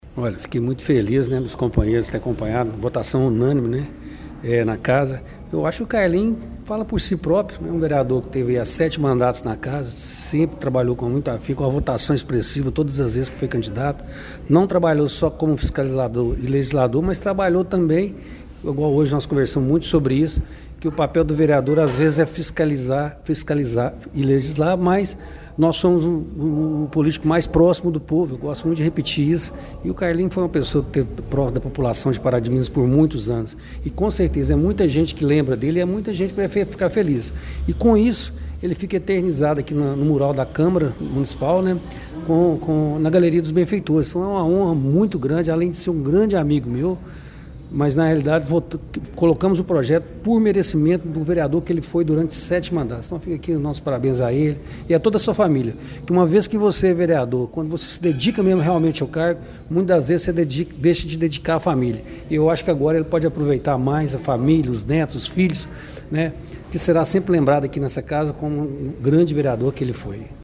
A Câmara Municipal de Pará de Minas realizou na tarde desta terça-feira, 19 de novembro, a 40ª reunião semanal ordinária no exercício de 2024.